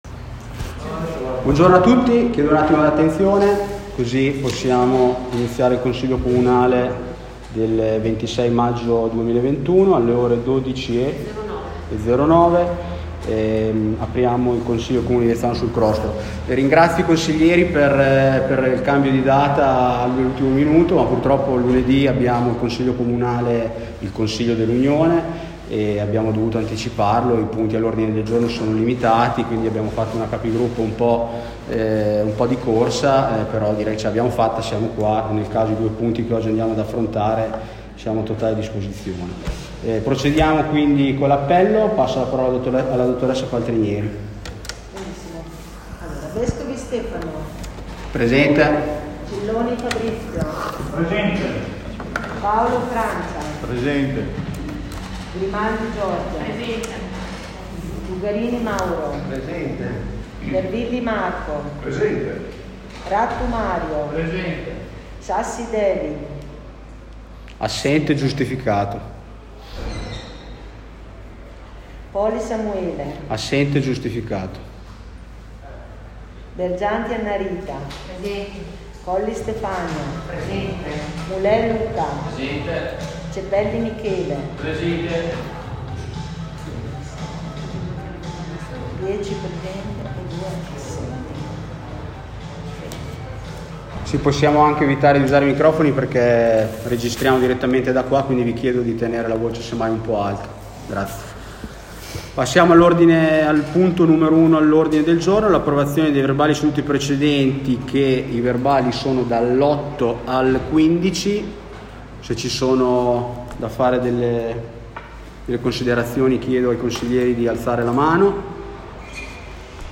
Consigli Comunali – Anno 2021